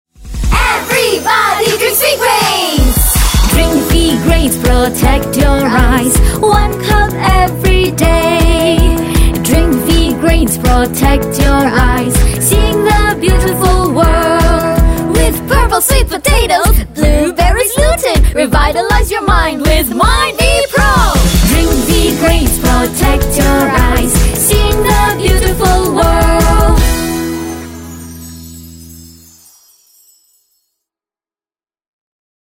Female
Energetic Young Corporate Fatherly/Motherly High-pitched Gravelly Smooth Conversational
Panasonic IT Vacuum Cleaner (Mandarin) Gravelly, sentimental.
Aptagro 2019 (Mandarin) Energetic yet motherly.